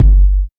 99 KICK 4.wav